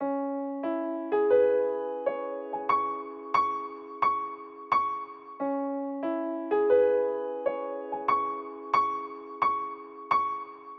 钢琴 钢琴 钢琴
Tag: 89 bpm Hip Hop Loops Piano Loops 1.82 MB wav Key : Unknown